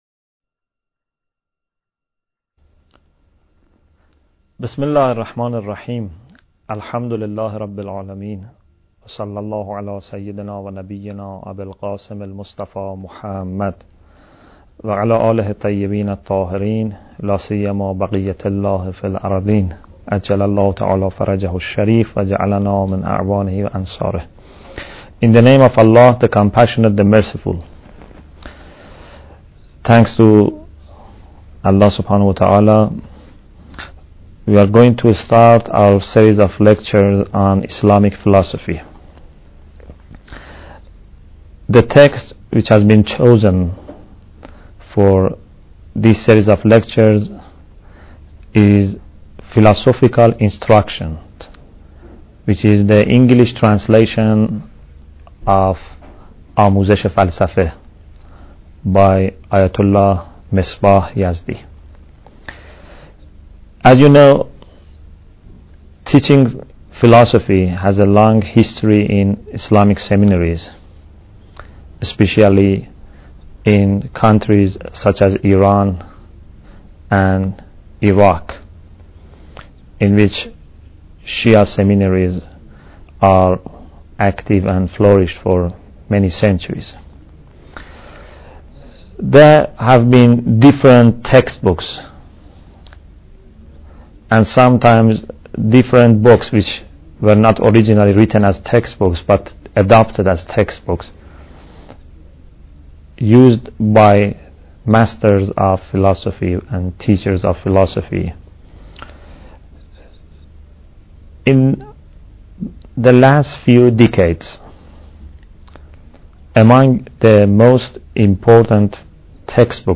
Bidayat Al Hikmah Lecture 1